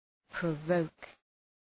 Προφορά
{prə’vəʋk}